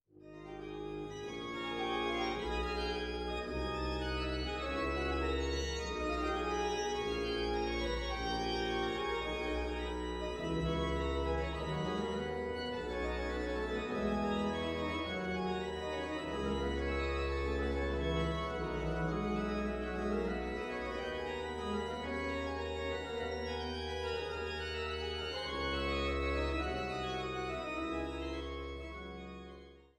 Bad Lausick